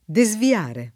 desviare [ de @ vi- # re ]